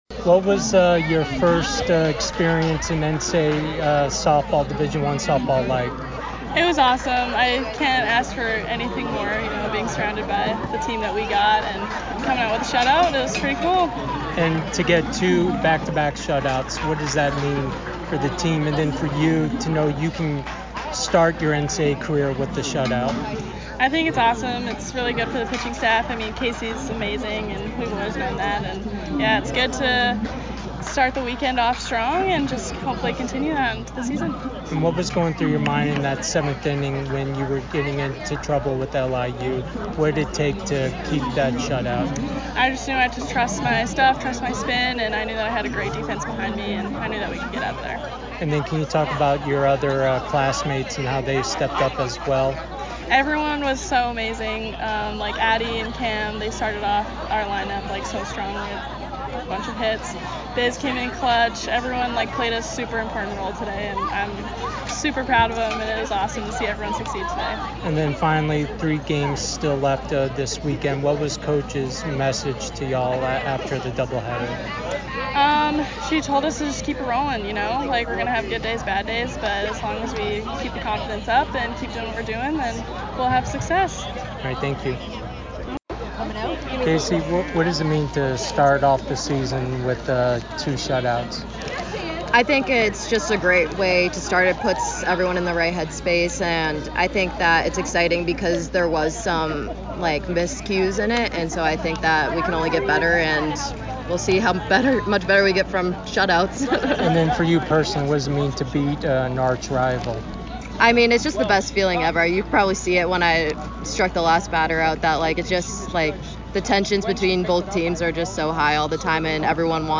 Softball / FGCU Kickoff Classic Day 1 Interview